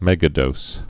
(mĕgə-dōs)